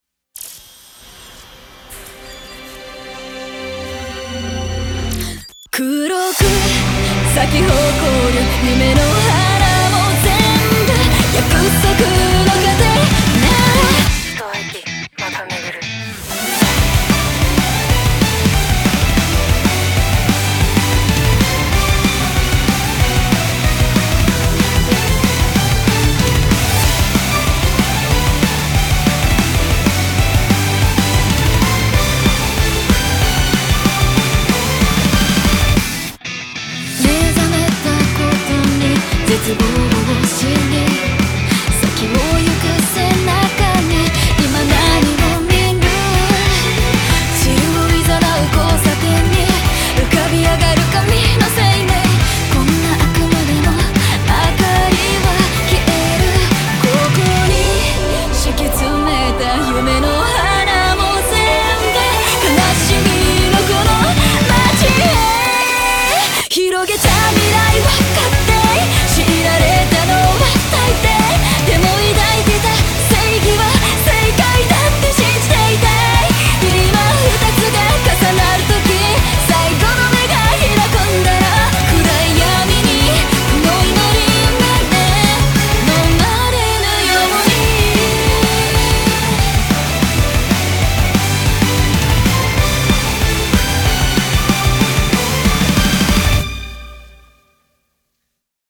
BPM94-188
Audio QualityCut From Video